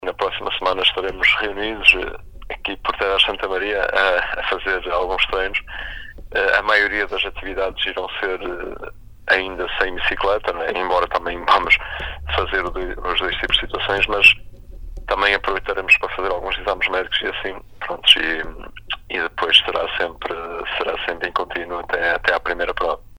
Em entrevista à Sintonia